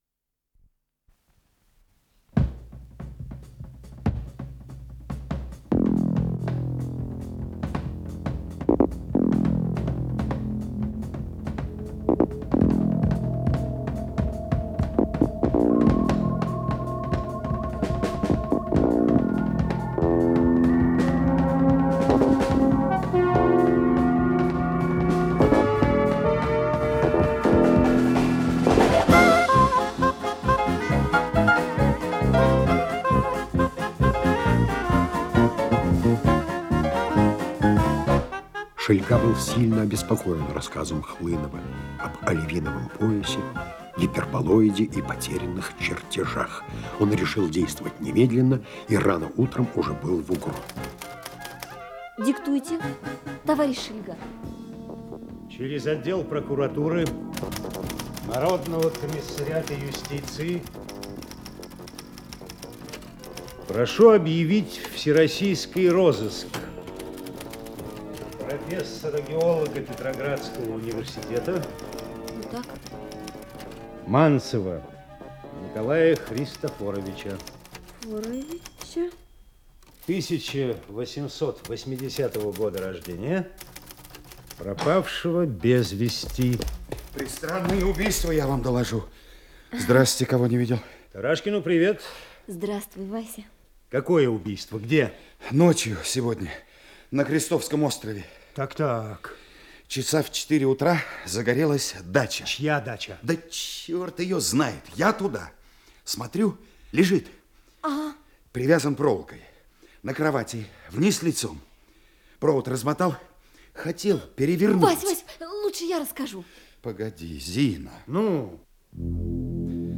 Исполнитель: Артисты московских театров